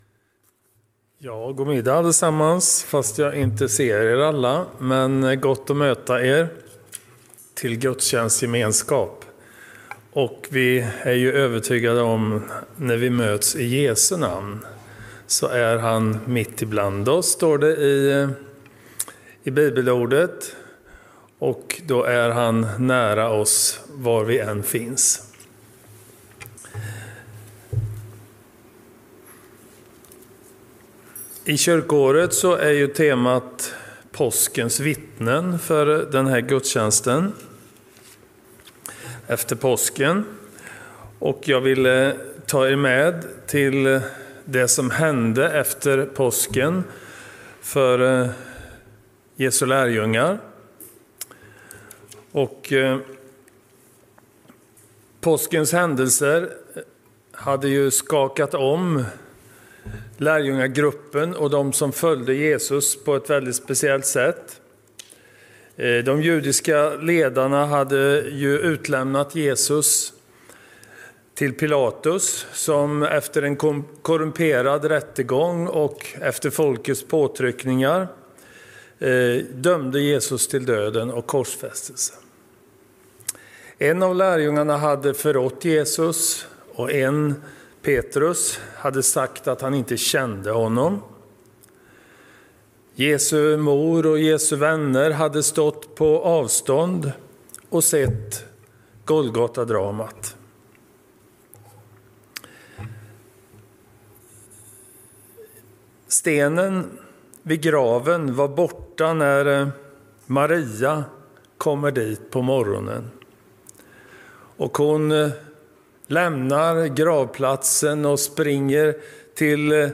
Gudstjänst i Centrumkyrkan i Mariannelund